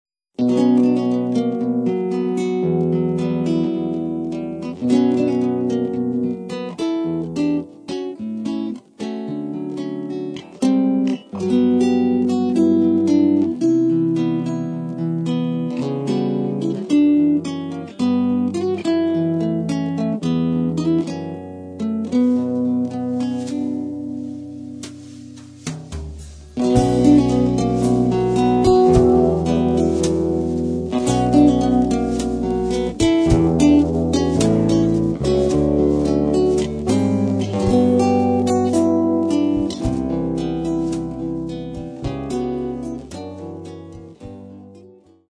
guitare (nylon & électrique)
Electric Guitar
Keyboards
Drums and percussions
Bass